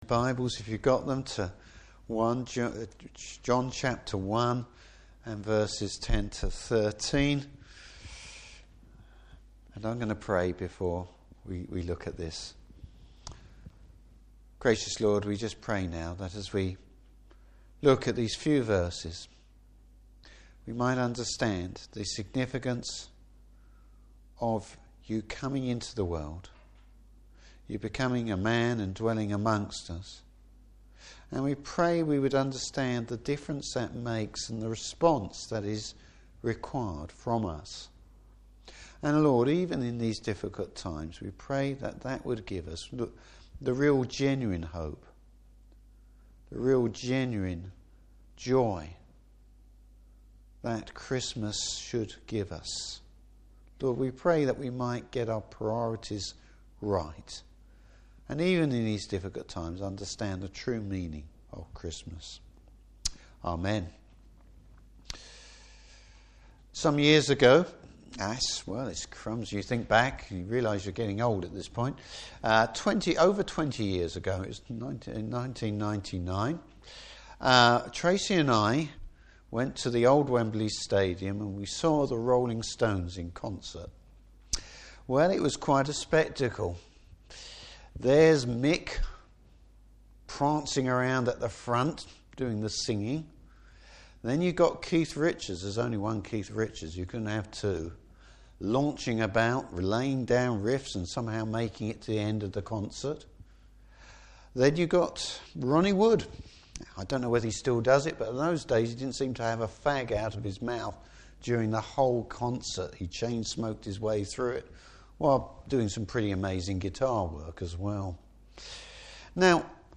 Service Type: Carol Service God’s gracious acceptance of those who believe.